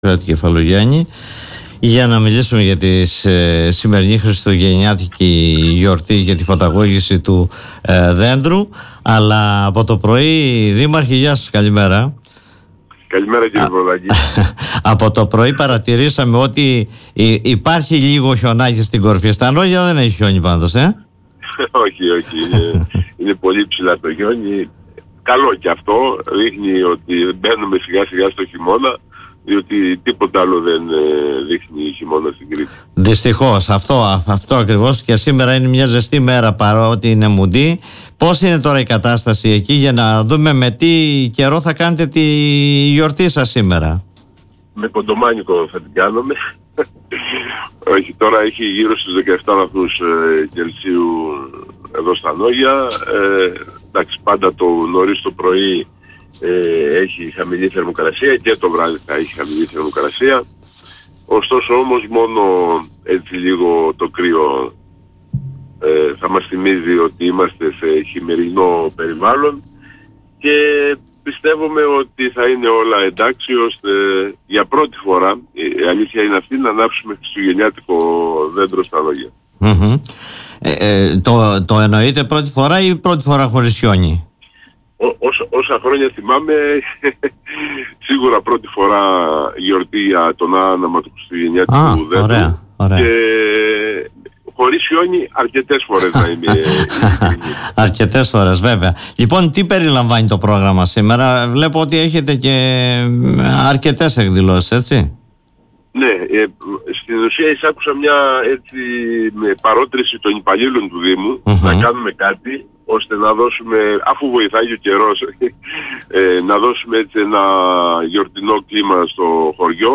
Ακούστε τι είπε ο δήμαρχος στην εκπομπή “Δημοσίως”